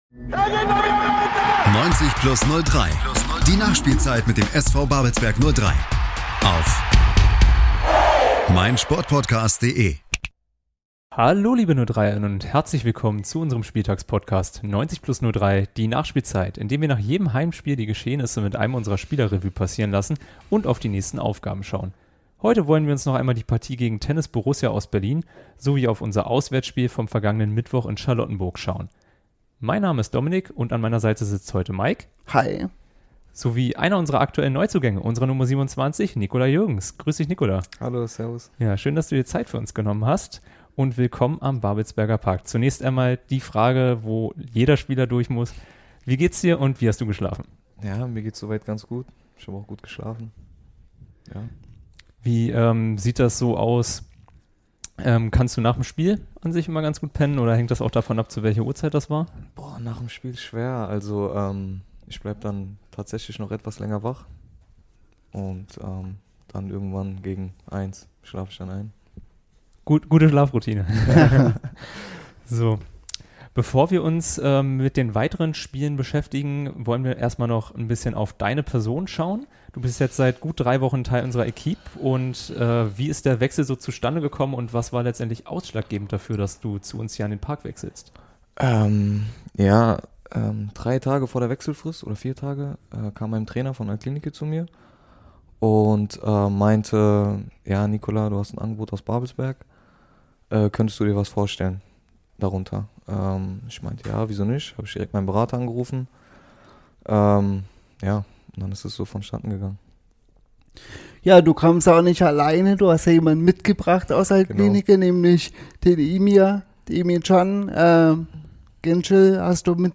Gemeinsam mit einem unserer Kiezkicker, der direkt vom Spielfeld ins Studio eilt, werden die vorangegangenen 90 Minuten ausführlich besprochen und ein Ausblick auf die kommenden Aufgaben des SV Babelsberg 03 gewagt.